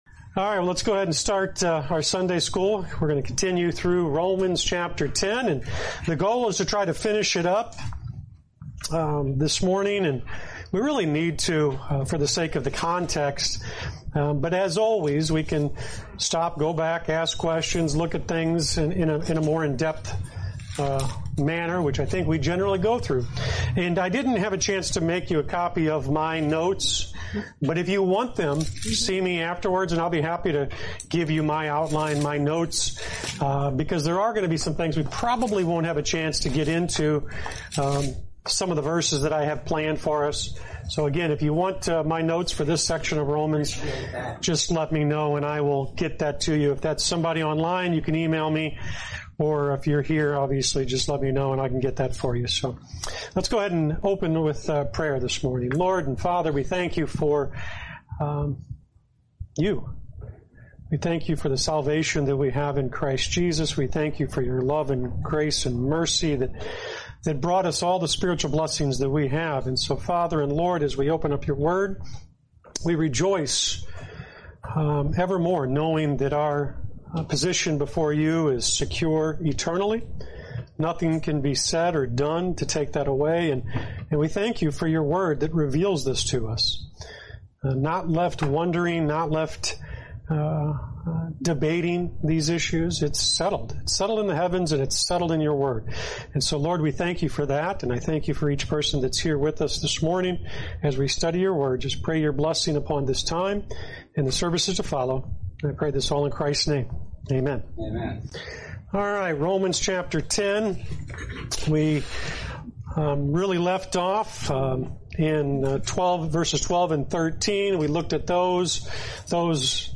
Lesson 66: Romans 10:13-17